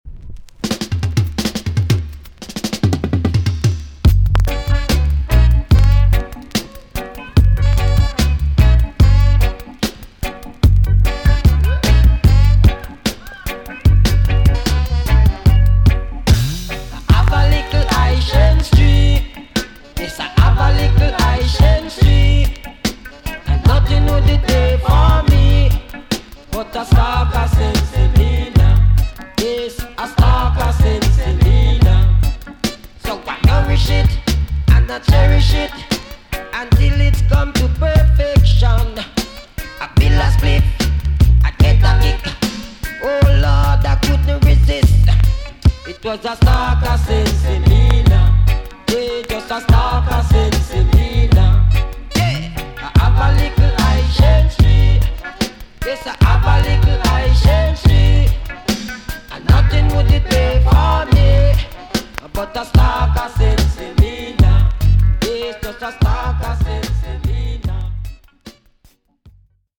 TOP >80'S 90'S DANCEHALL
VG+~VG ok 前半良好ですが後半プレス起因による凹凸があり軽いチリノイズが入ります。